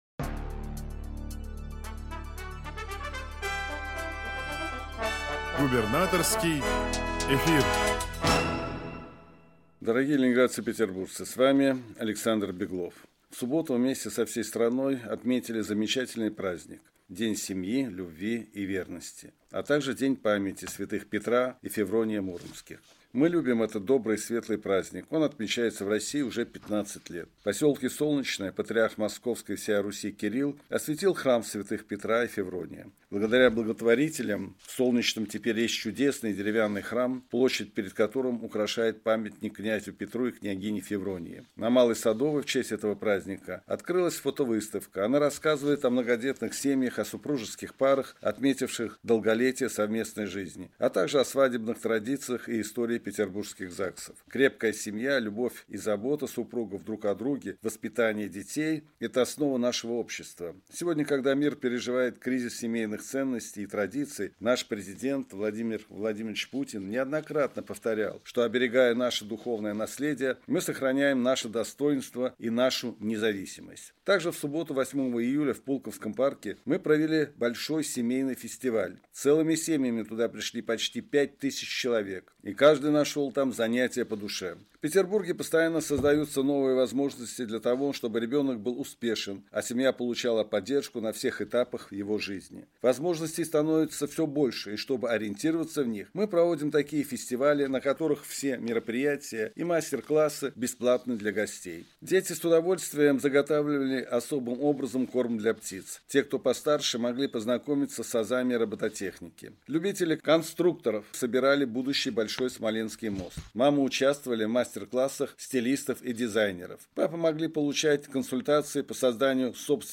Радиообращение 10 июля